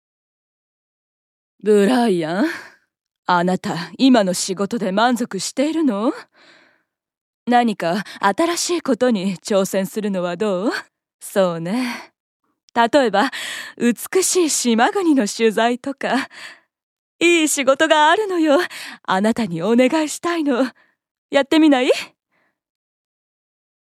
◆壮年期の女性上司◆